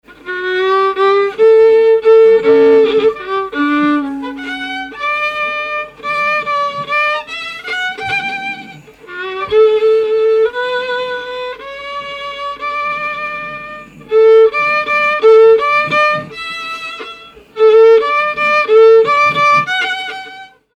Sixt-Fer-à-Cheval
Pièce musicale inédite